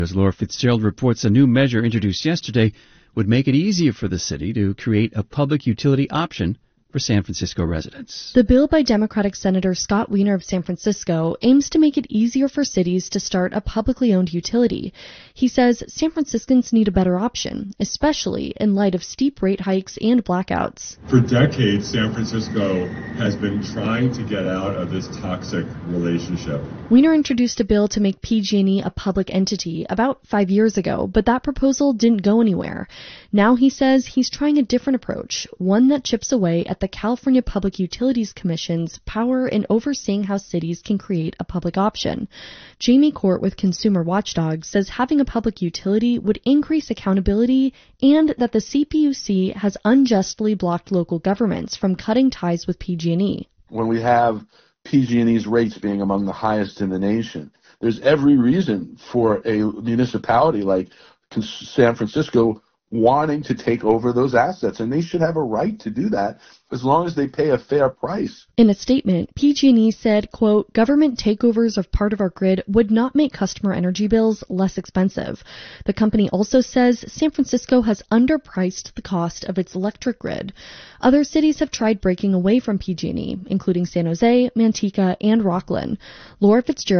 CapitalPublicRadioMorningDrive900AM.mp3